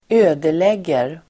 Uttal: [²'ö:deleg:er]